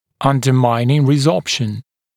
[ˌʌndə’maɪnɪŋ rɪˈzɔːpʃn] [-ˈsɔːp-][ˌандэ’майнин риˈзо:пшн] [-ˈсо:п-]подрывающая резорбция